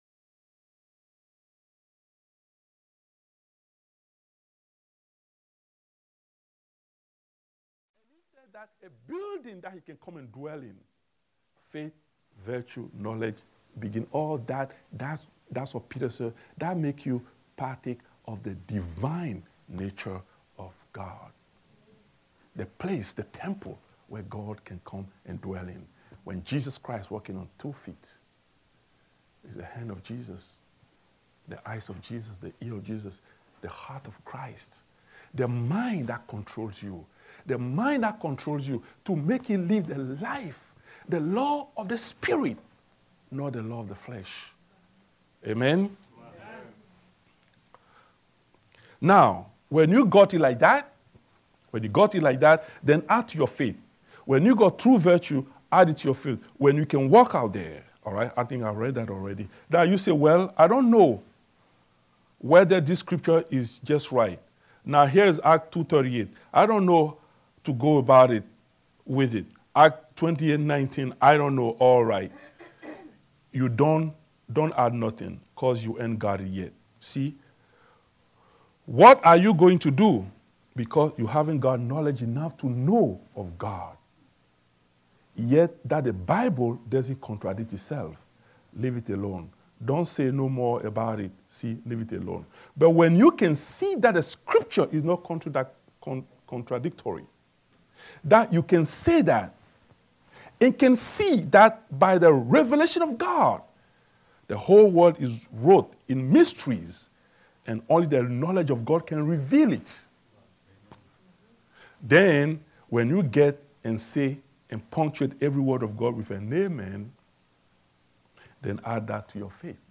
Series: Sunday school